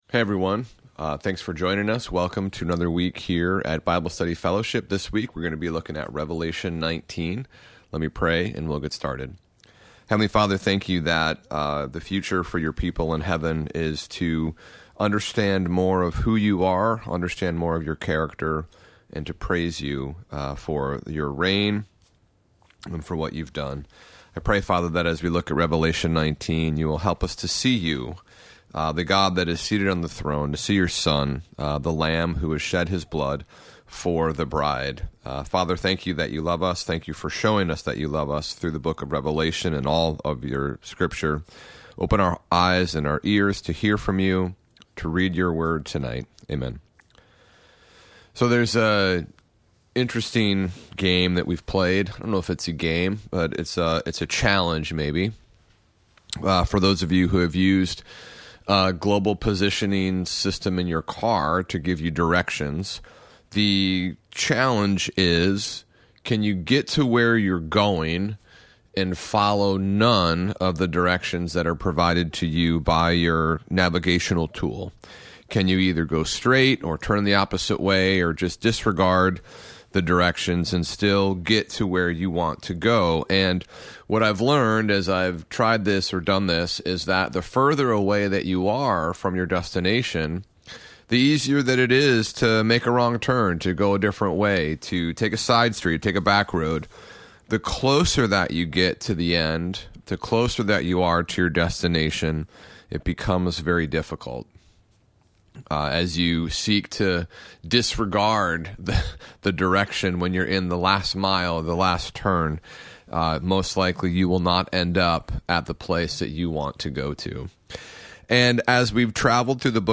Weekly Bible Teaching